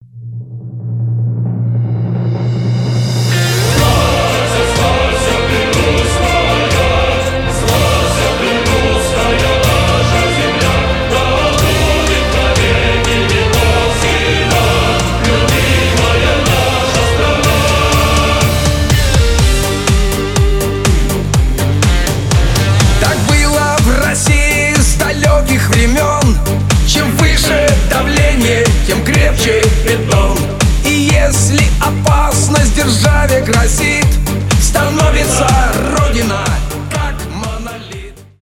поп
патриотичные , эстрада , эстрадные